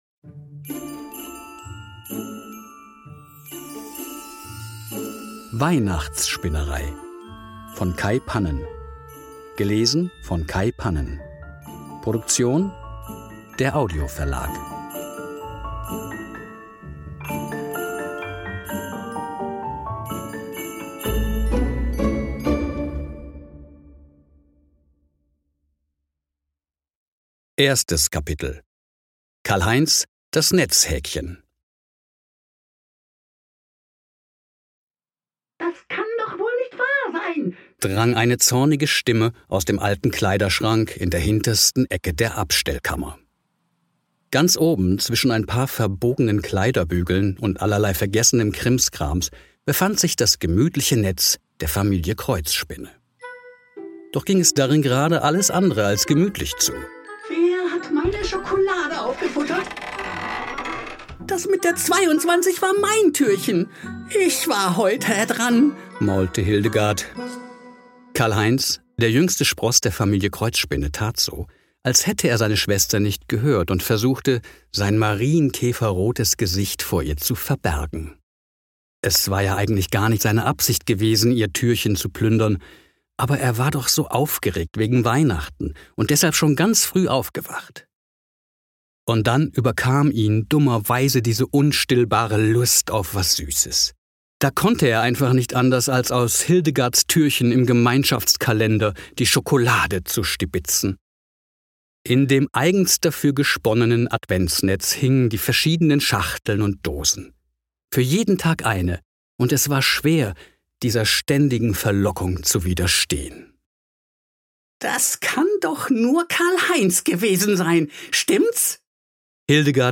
Hörbücher, Hörspiele für Kinder, Kinder- und Jugend-Hörbücher, Weihnachtsgeschichten- und lieder